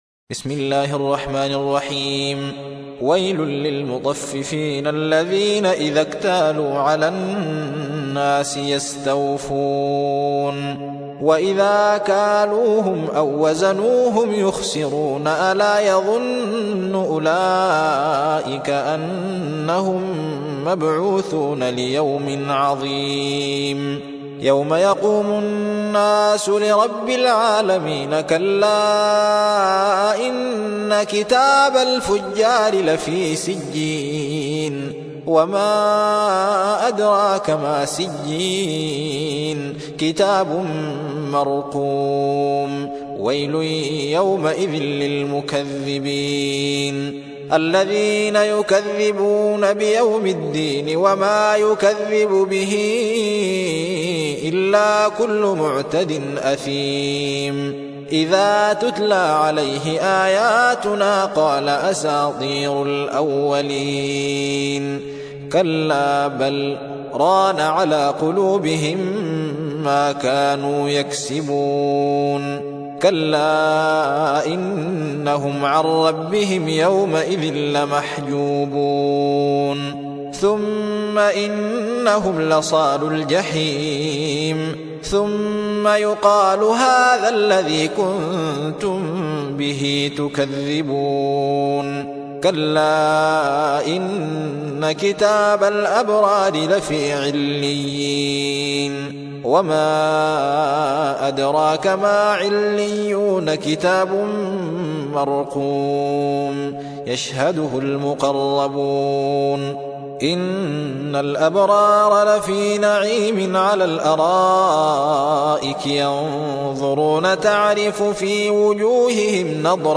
83. سورة المطففين / القارئ